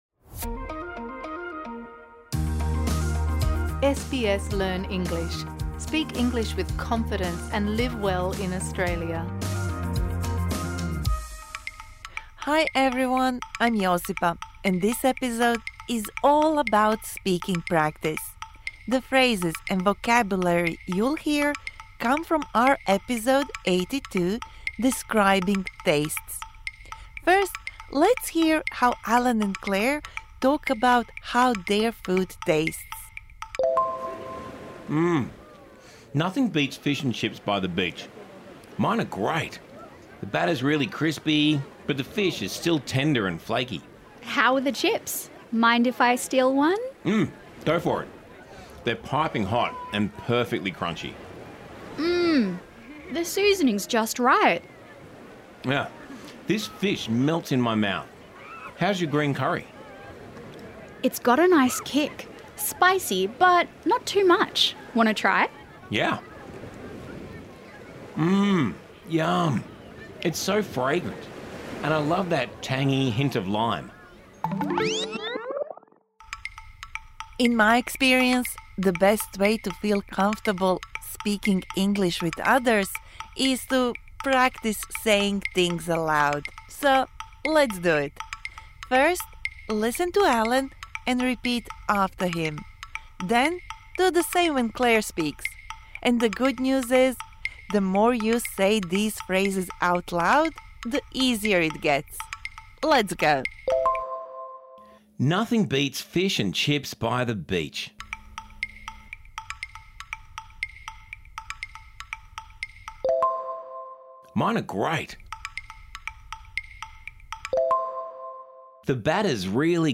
Practise speaking the dialogue from episode #82 Describing taste (Med).
This bonus episode provides interactive speaking practice for the words and phrases you learnt in Episode #81 Talking about changing habits Don't be shy - just try!